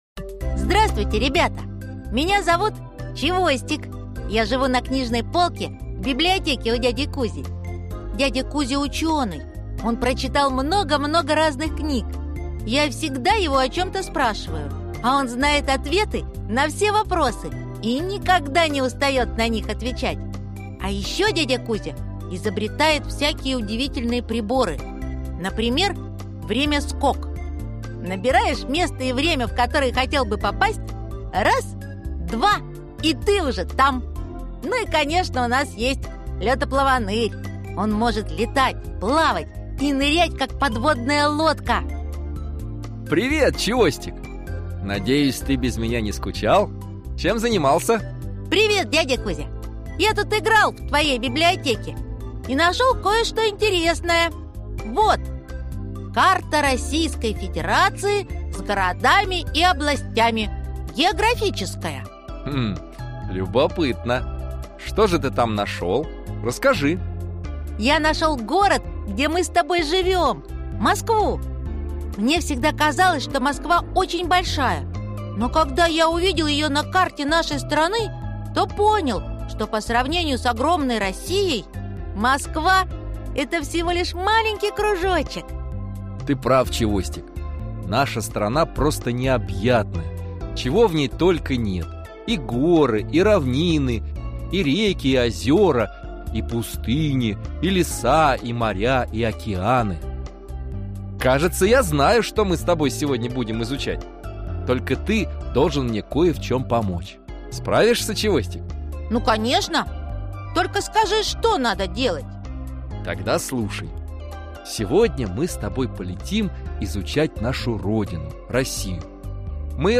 Аудиокнига Камчатка | Библиотека аудиокниг